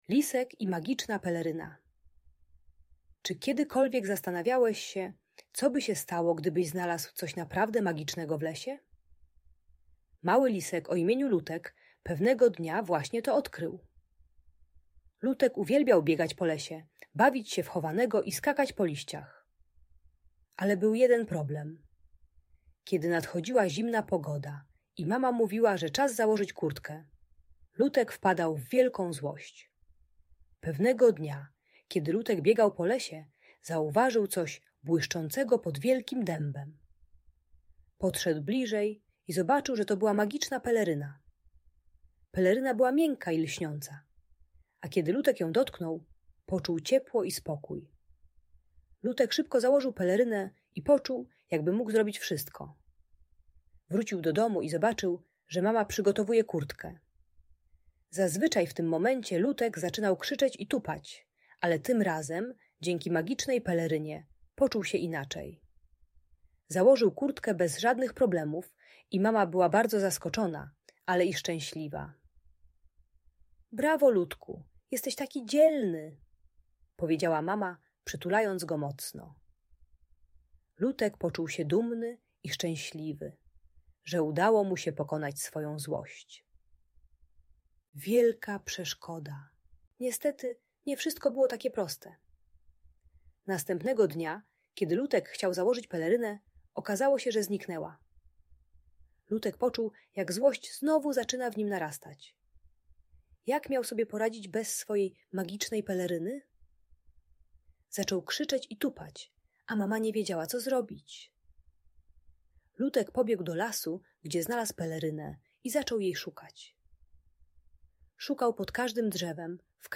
Lisek i Magiczna Peleryna - Bunt i wybuchy złości | Audiobajka